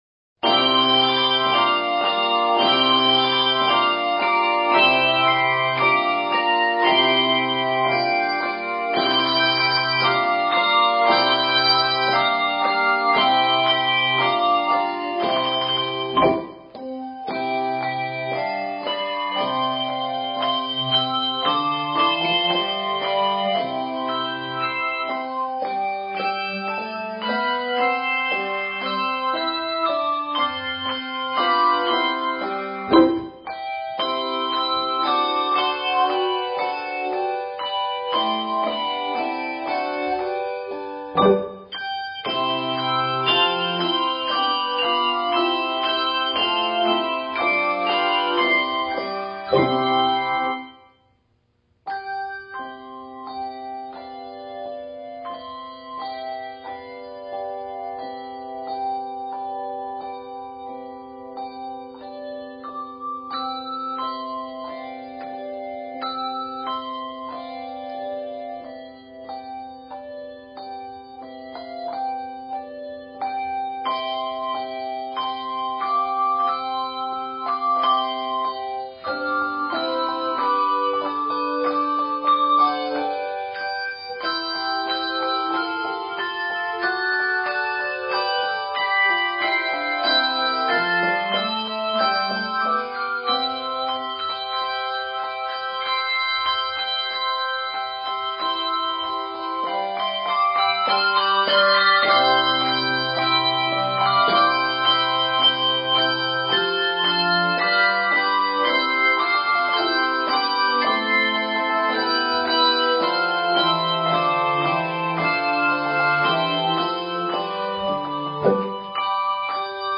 handbell choir